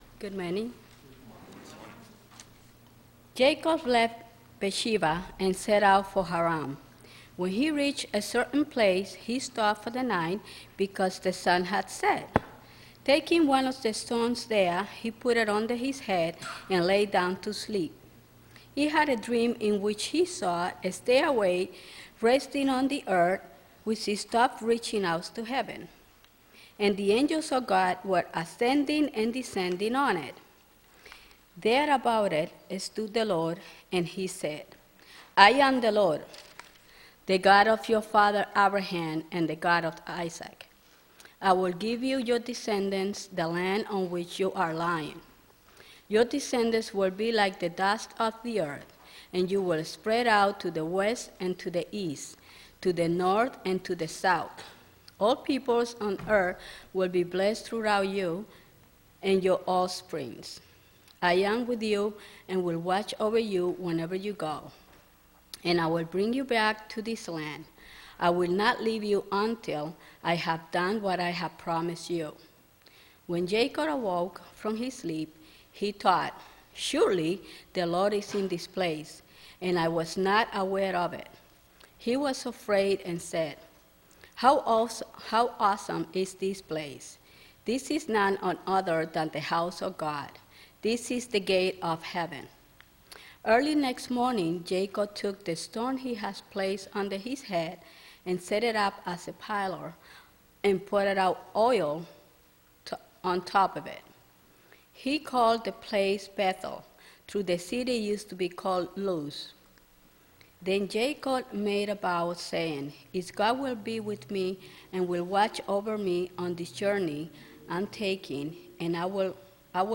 Audio Recording of April 19th Worship Service – Now Available
The audio recording of the April 19th Worship Service is now available.